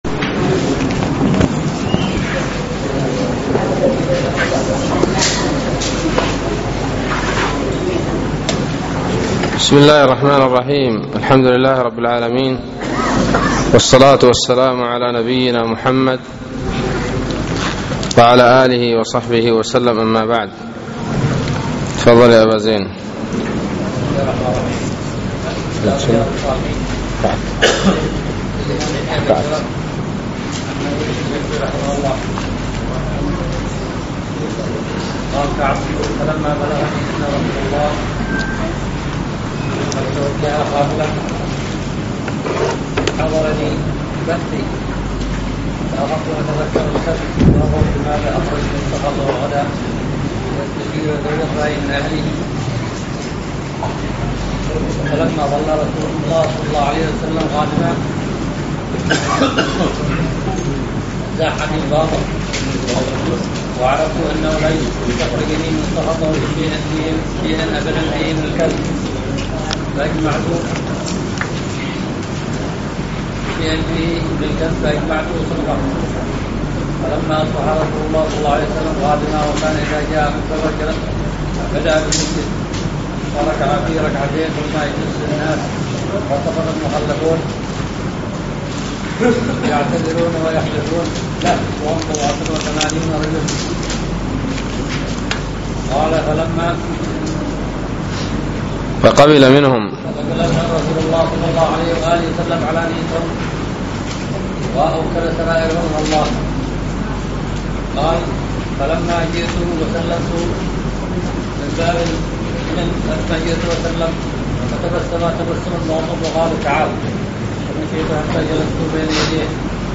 الدرس السادس والأربعون من شرح كتاب التوحيد